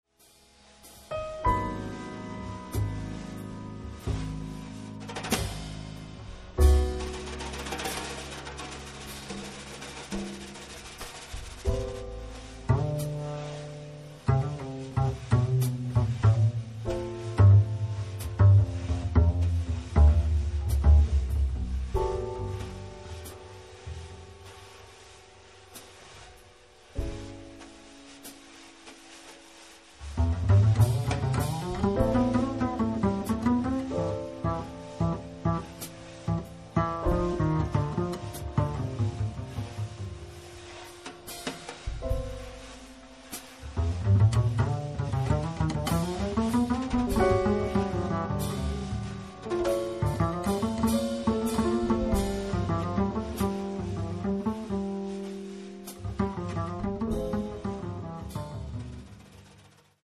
contrabbasso
pianoforte
batteria